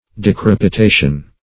Decrepitation \De*crep`i*ta"tion\, n. [Cf. F.